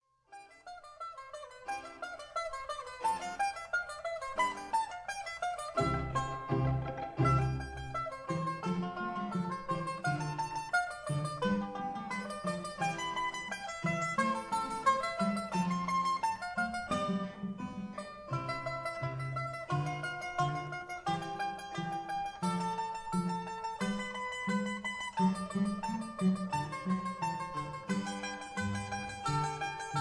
for mandolin, strings and harpsichord